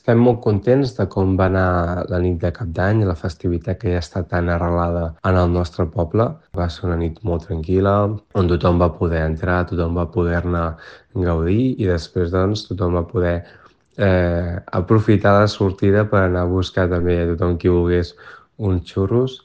El regidor de Festes, Aniol Canals, destacava la tranquil·litat de la jornada.